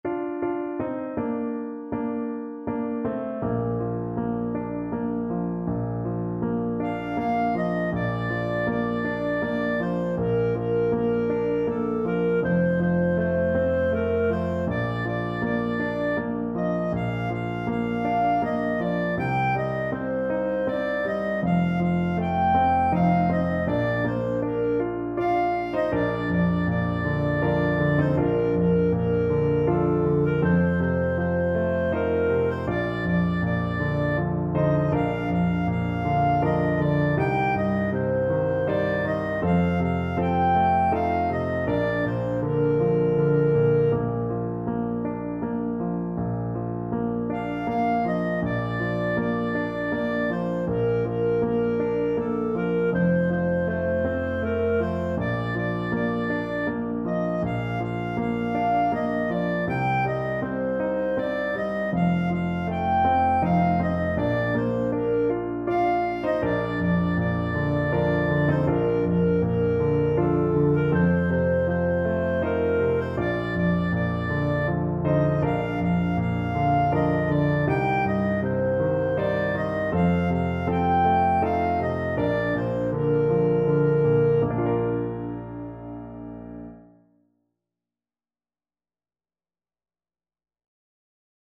Andante